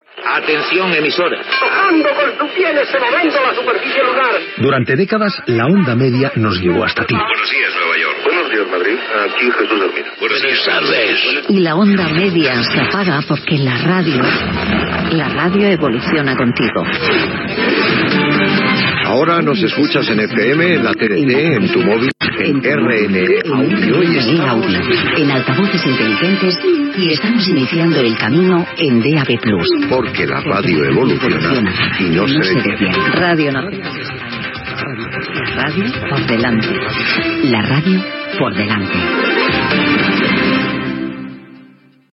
Primer anunci avisant del tancament de les emissions en Ona Mitjana de RNE
Info-entreteniment